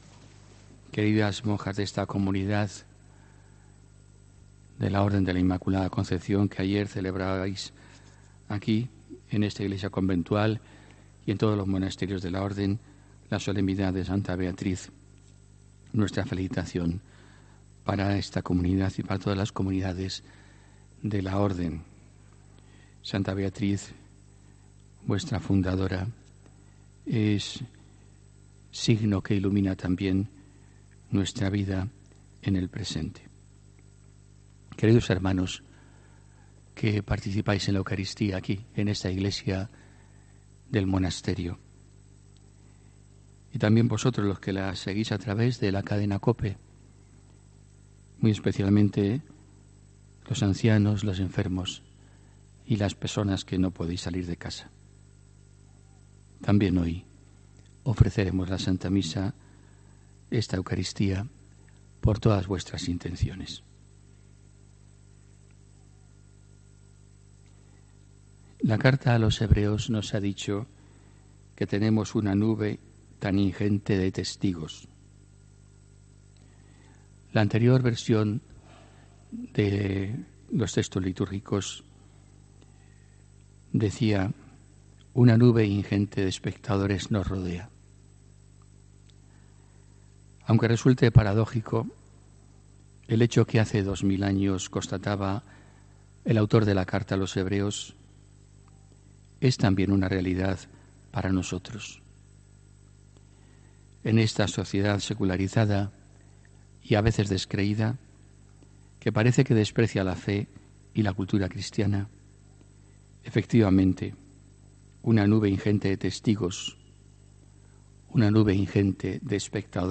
HOMILÍA 18 AGOSTO 2019